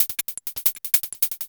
Hats 03.wav